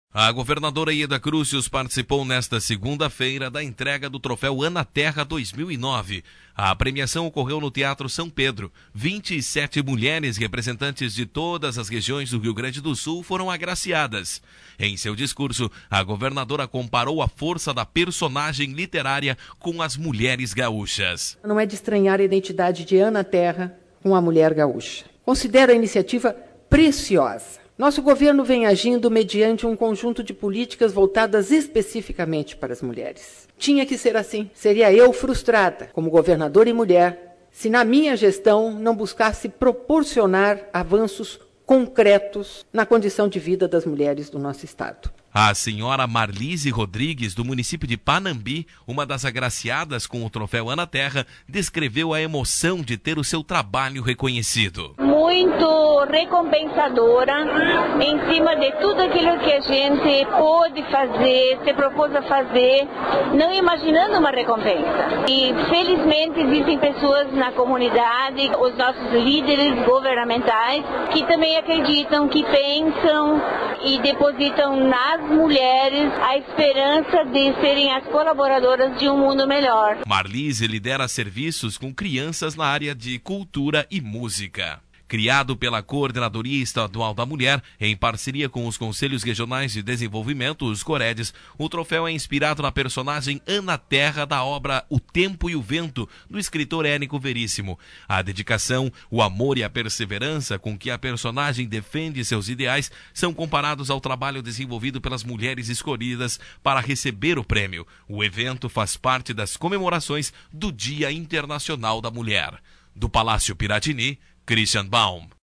A governadora Yeda Crusius participou nesta segunda feira (09) da entrega do troféu Ana Terra 2009. A premiação ocorreu no Theatro São Pedro.